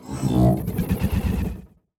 Robotic Notification 4.wav